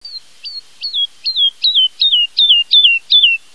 La Tottavilla (Lullula arborea) è un altro rappresentante della famiglia delle allodole (Alaudidae) diffuso nelle nostre zone, specie nei campi coltivati e nei prati collinari con arbusti ed alberi sparsi.
Canta anch'essa mentre si libra alta nel cielo, ma il suo ritornello è molto differente da quello dell'Allodola.